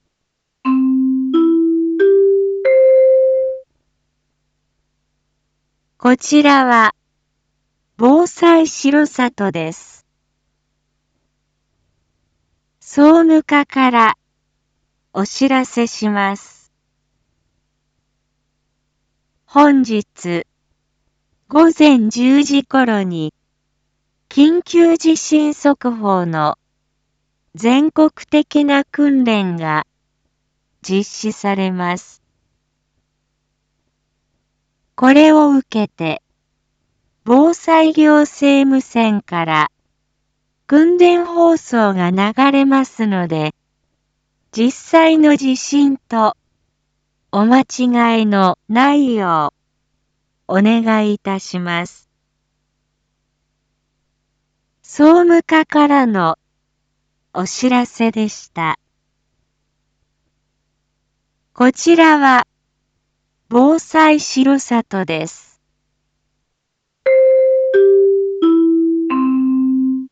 Back Home 一般放送情報 音声放送 再生 一般放送情報 登録日時：2022-11-02 07:01:11 タイトル：当日・全国瞬時警報システム訓練 インフォメーション：こちらは、防災しろさとです。